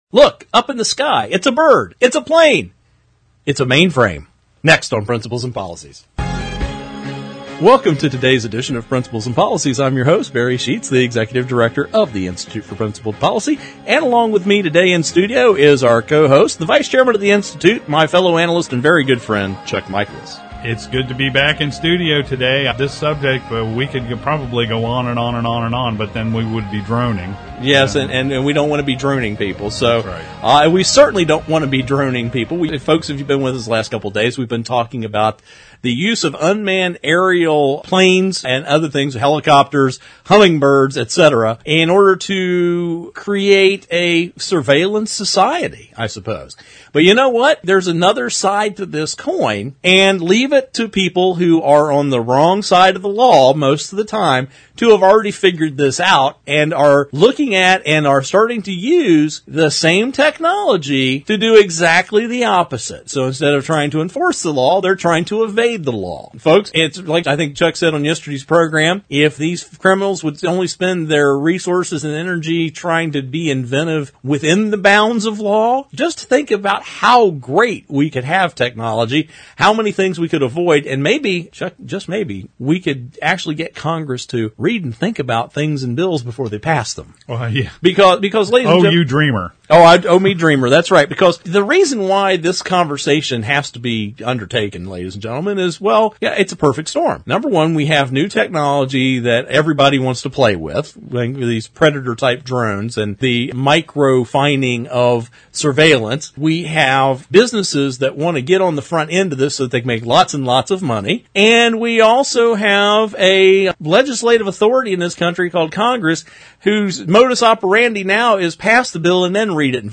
Our Principles and Policies radio show for Monday April 1, 2013.